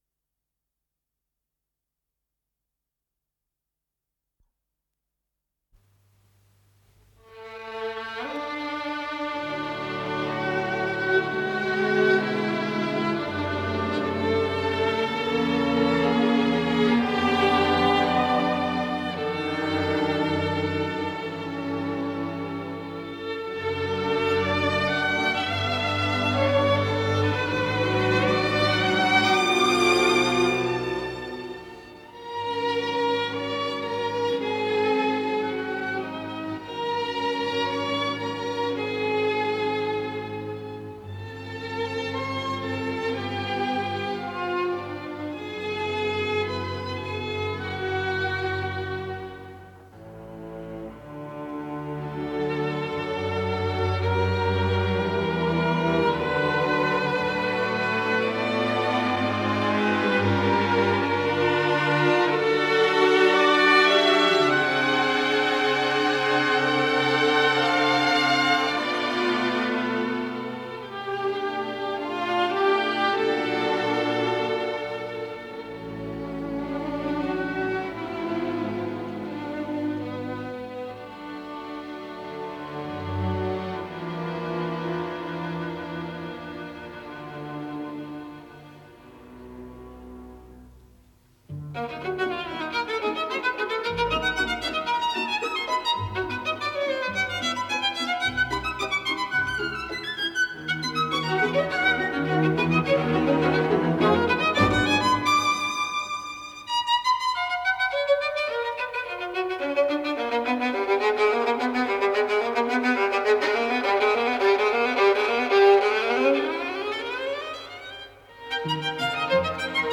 Исполнитель: Ансамбль солистов Государственного Академического симфонического оркестра СССР
Симфониетта
для малого оркестра, си минор